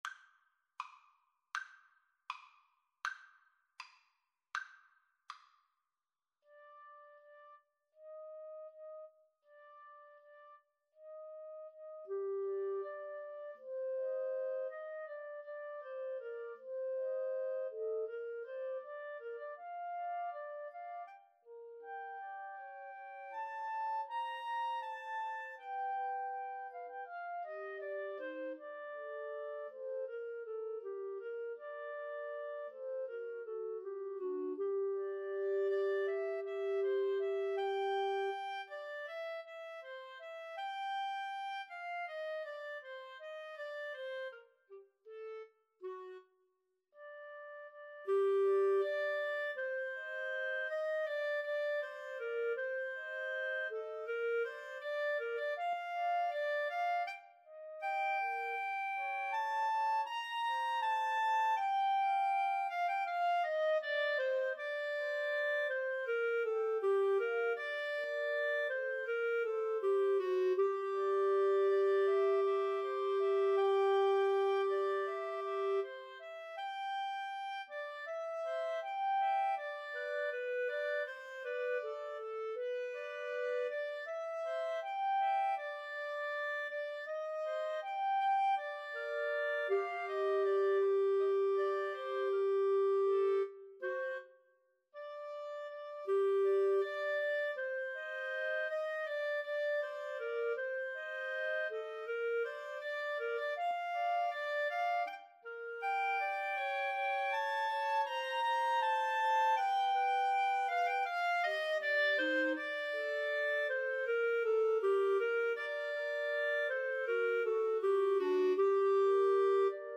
~ = 100 Andante
Classical (View more Classical Clarinet Trio Music)